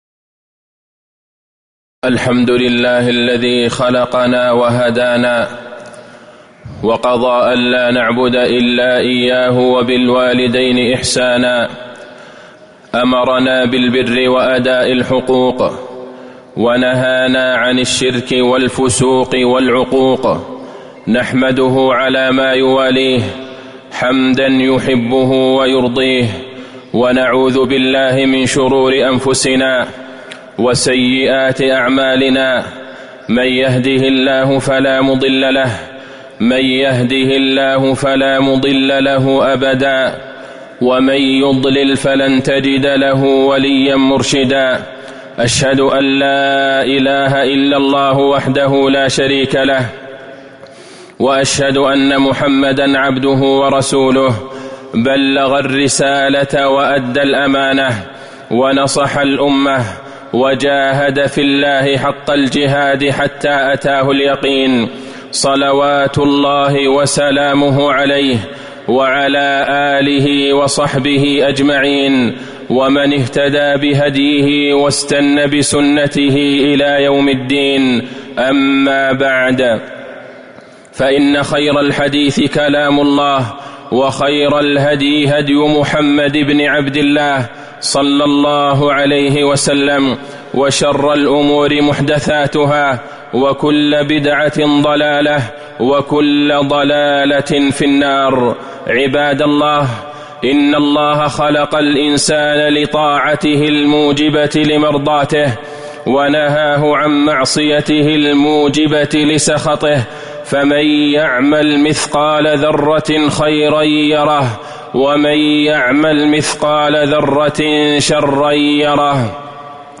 تاريخ النشر ٤ ربيع الأول ١٤٤٤ هـ المكان: المسجد النبوي الشيخ: فضيلة الشيخ د. عبدالله بن عبدالرحمن البعيجان فضيلة الشيخ د. عبدالله بن عبدالرحمن البعيجان بر الوالدين